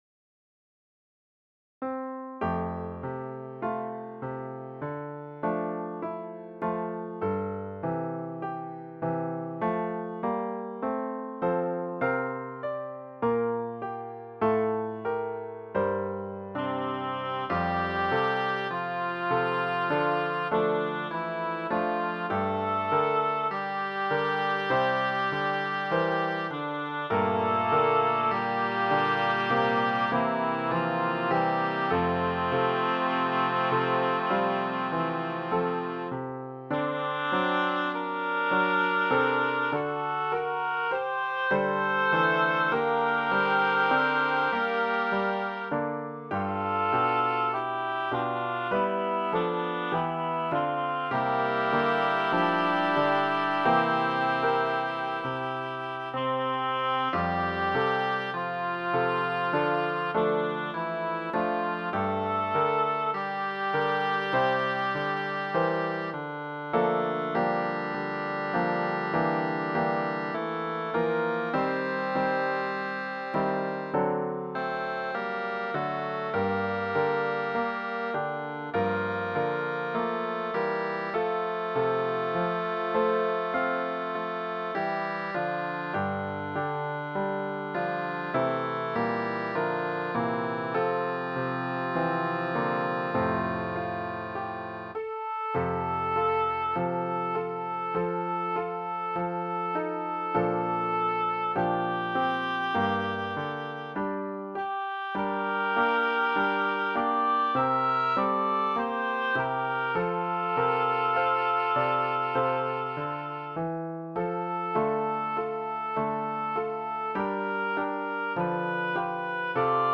Choral arrangment of a popular Primary Song.
Voicing/Instrumentation: SATB